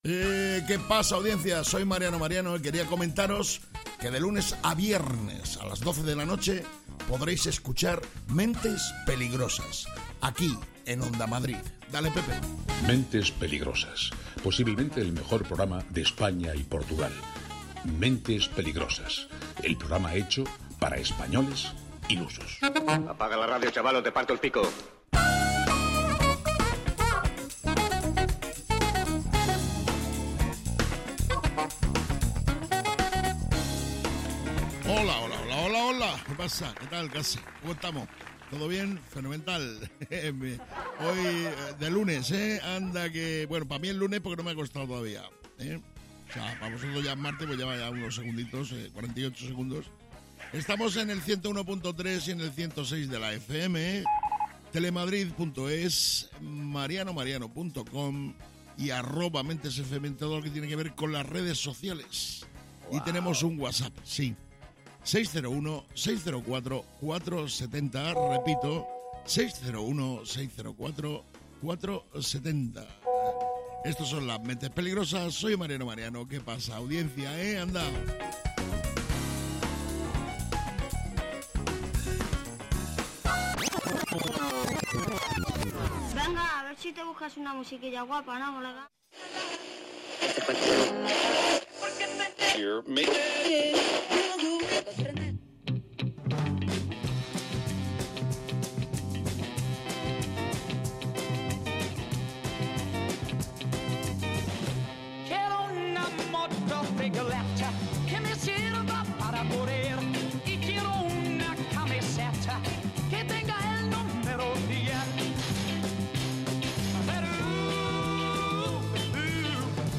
¿Es un programa de humor?